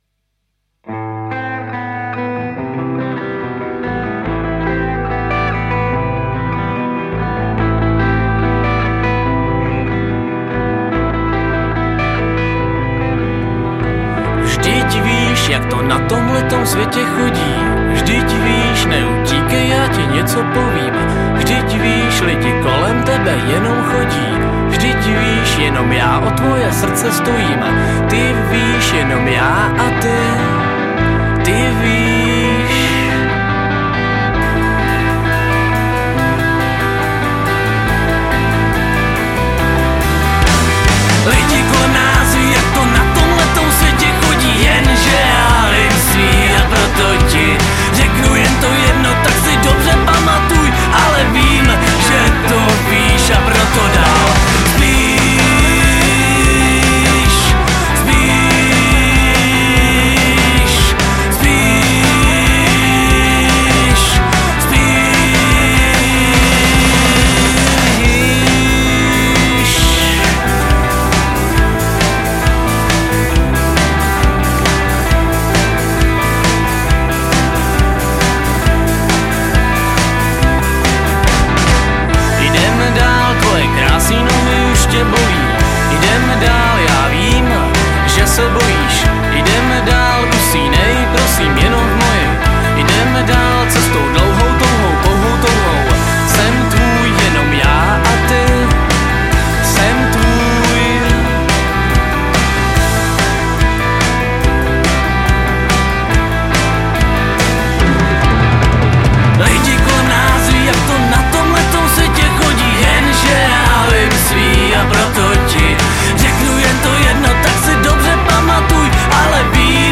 Žánr: Punk
Nahráno v listopadu 2015 v Táboře.